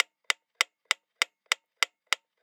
Metronome Fast.wav